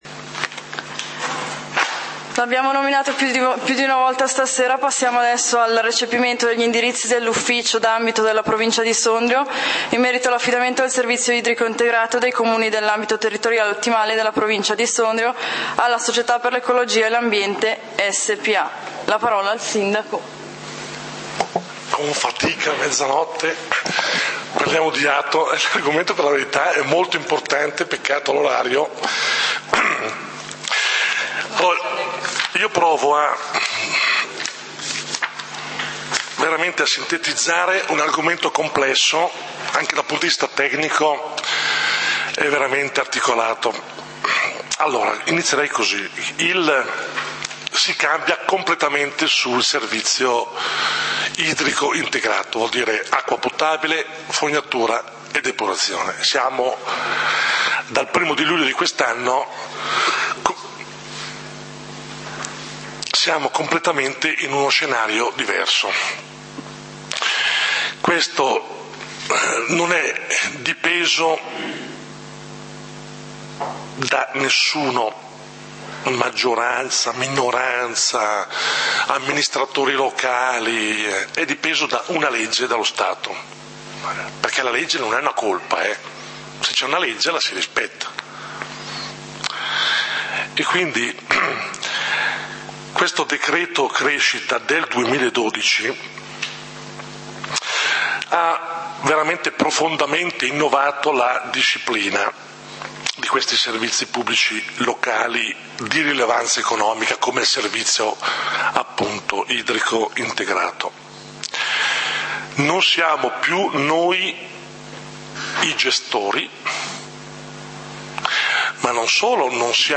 Consiglio comunale di Valdidentro del 24 Luglio 2014